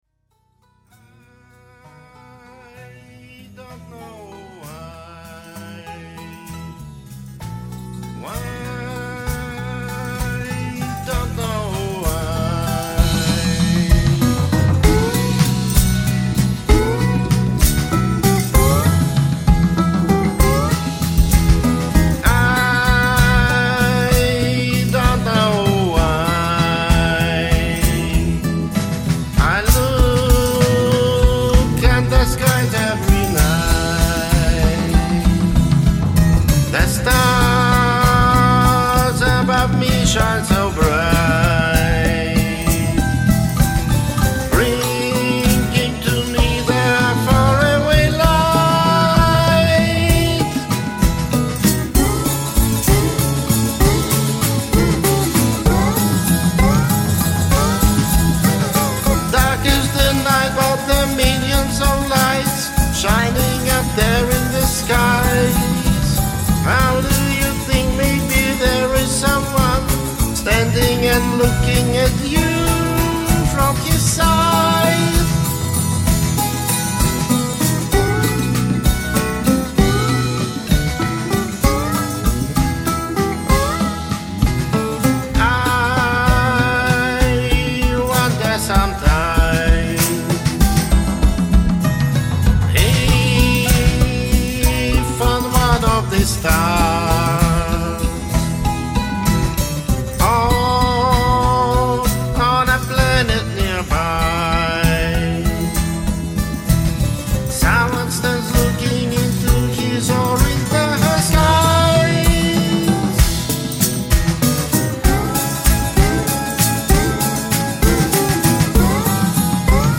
Stars are the eyes (Rock)